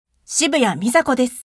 ボイスサンプル、その他
あいさつ